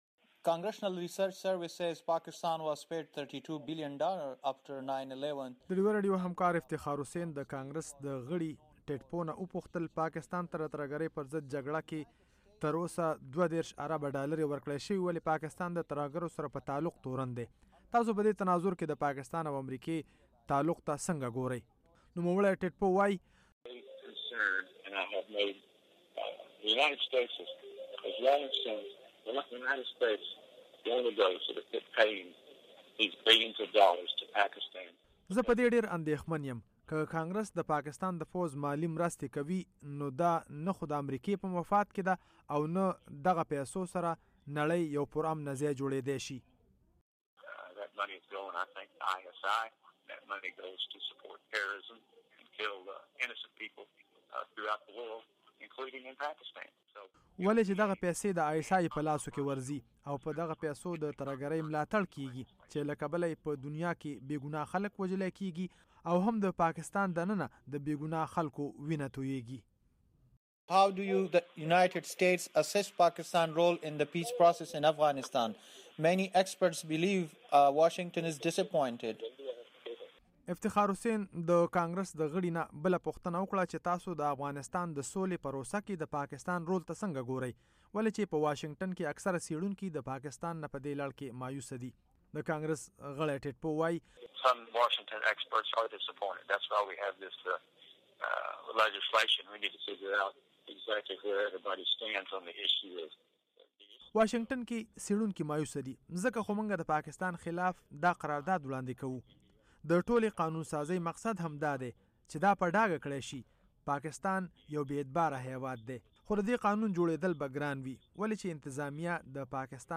ټیډ پو مرکه